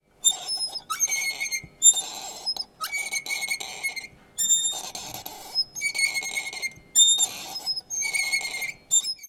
Muelles chirriando
chirriar
muelle
Sonidos: Hogar